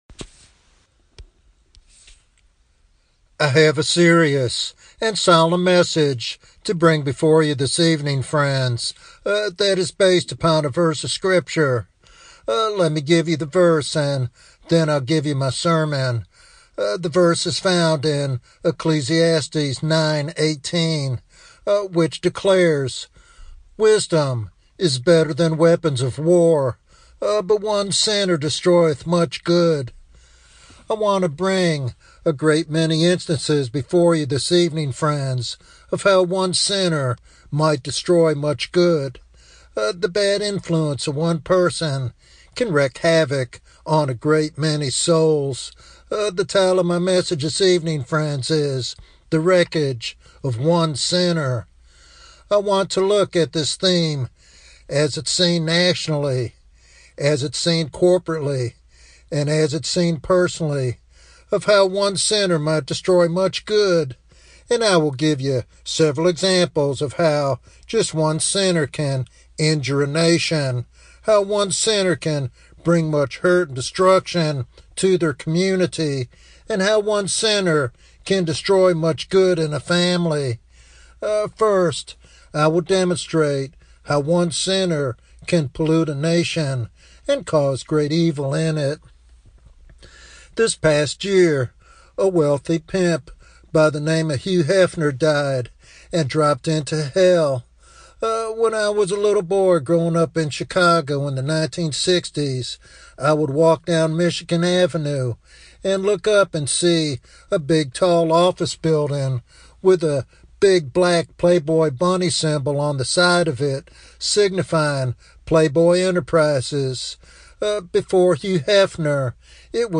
This topical sermon challenges listeners to examine their lives and choose the path of righteousness before it is too late.
Sermon Outline